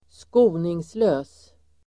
Ladda ner uttalet
Uttal: [²sk'o:ningslö:s]